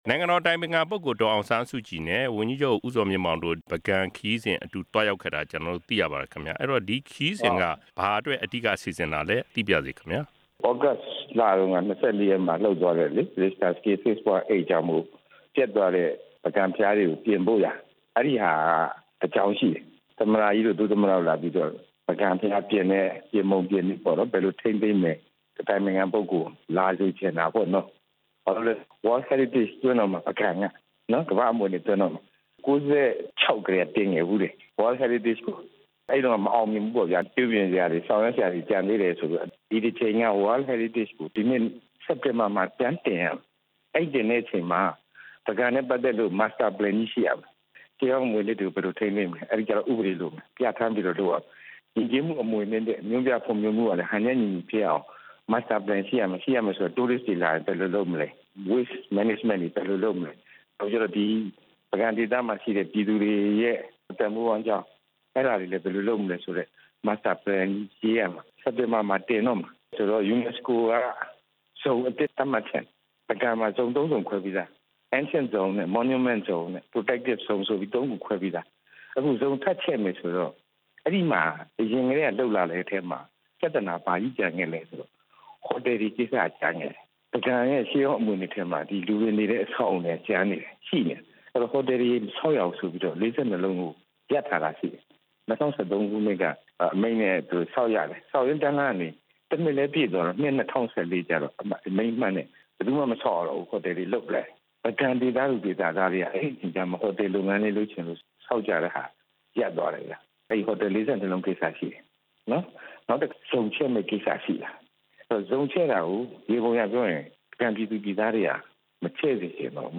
ဒေါက်တာ ဇော်မြင့်မောင်နဲ့ မေးမြန်းချက်